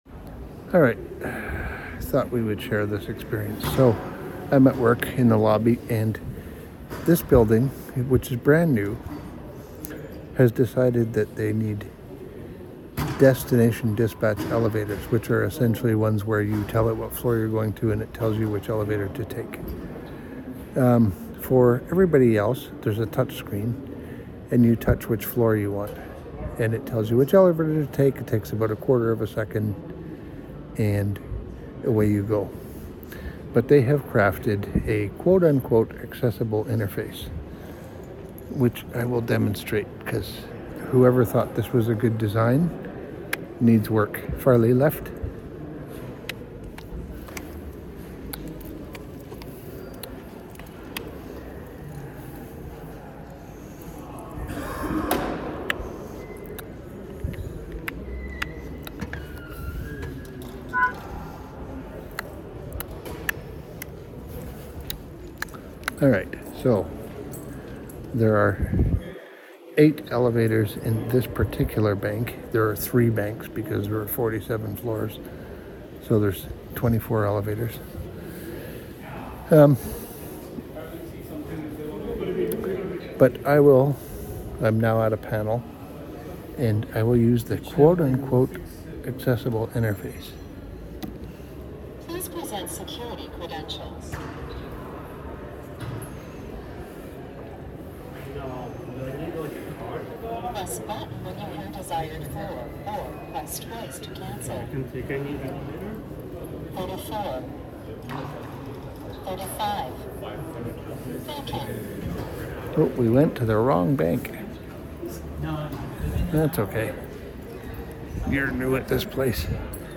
Welcome to a horrible elevator system